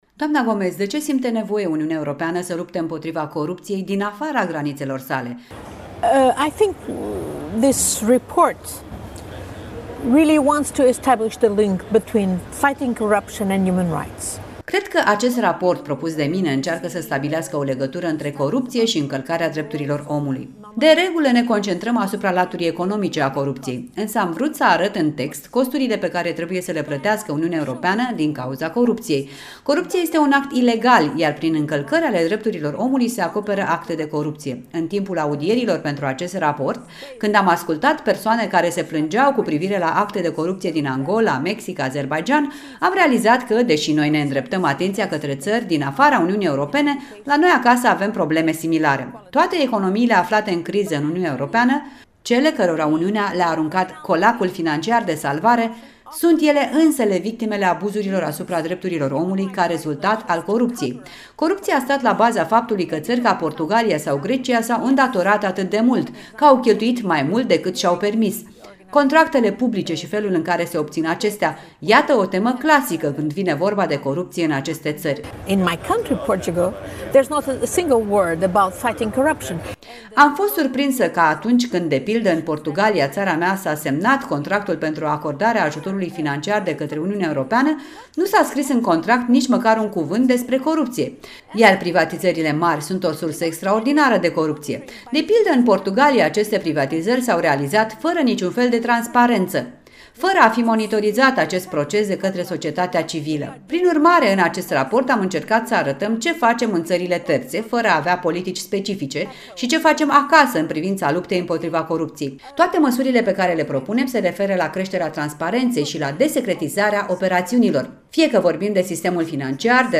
Un interviu cu europarlamentara portugheză Ana Gomes